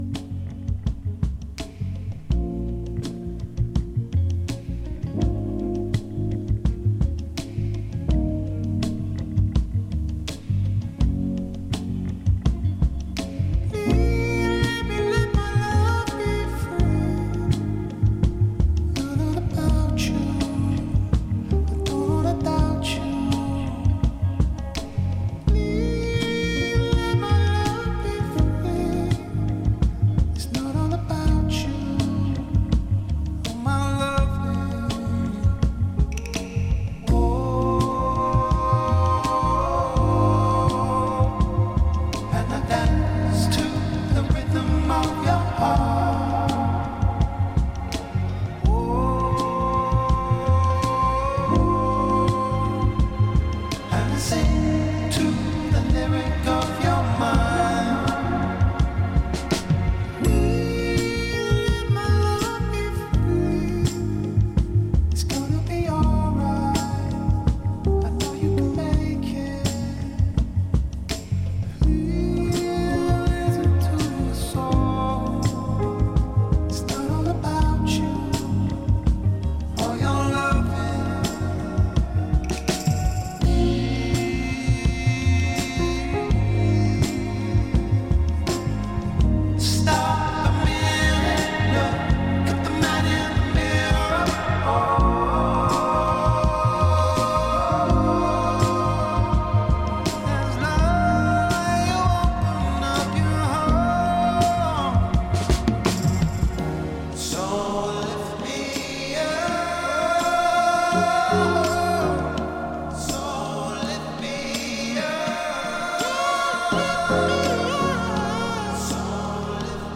Funk, Psychedelic, Soul